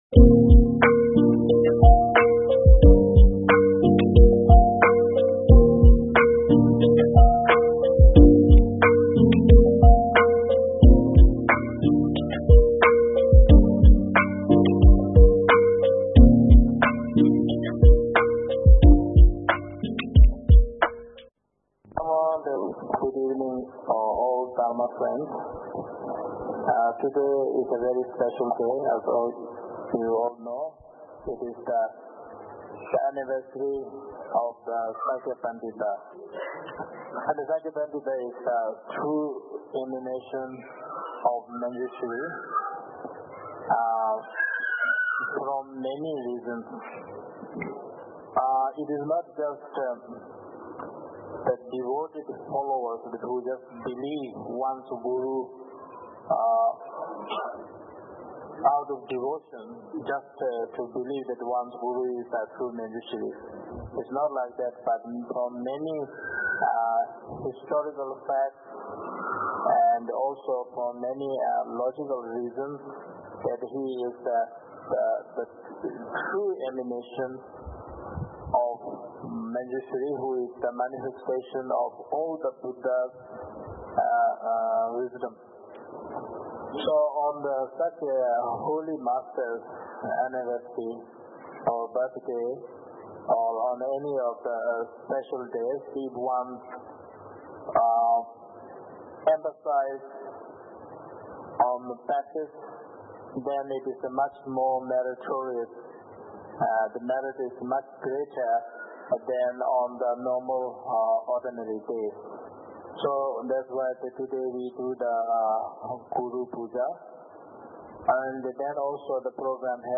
Venue: Sakya Tenphel Ling, Singapore